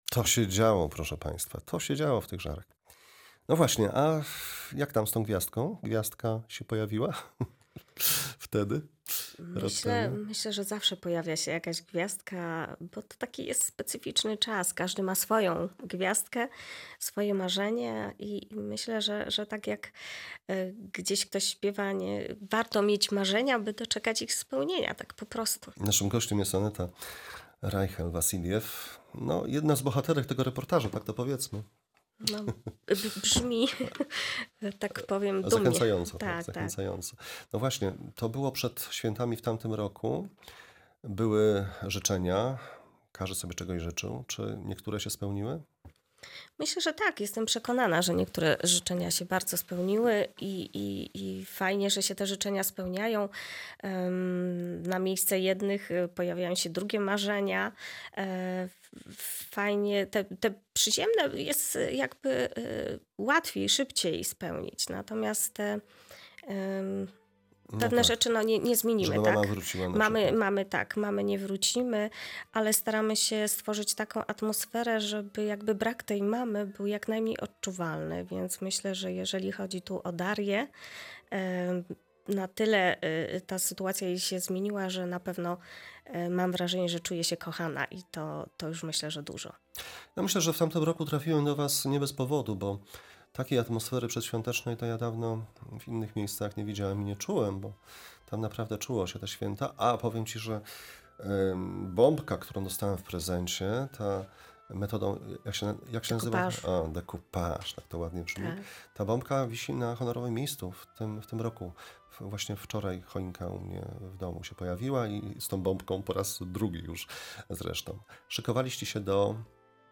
W ubiegłym nagrałem z tej okazji nagrałem cykl dokumentalny, którego II część chcę Państwu przypomnieć. Niezwykła emocjonalność, szczerość i potrzeba bycia razem.